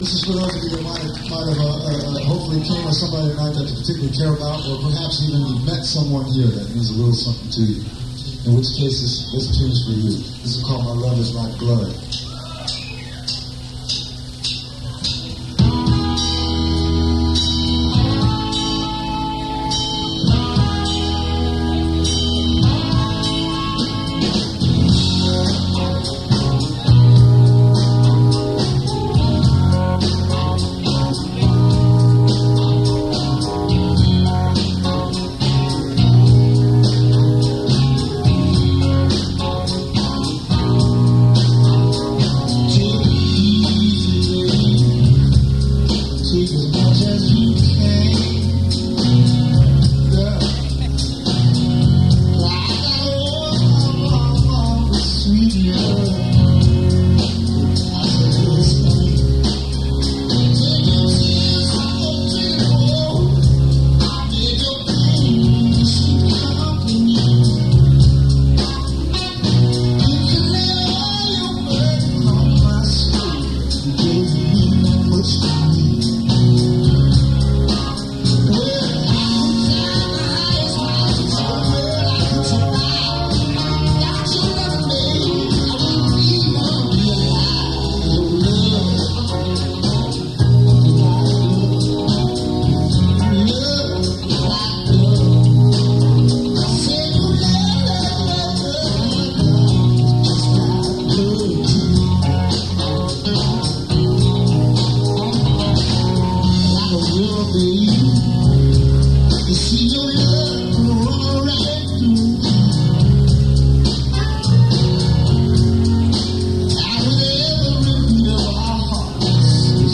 Attn: muddy and tinny sound